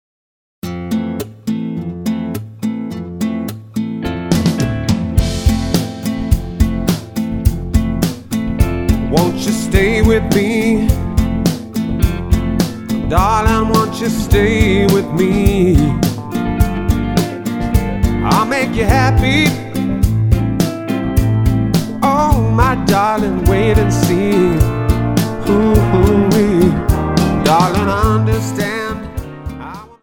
Tonart:F Multifile (kein Sofortdownload.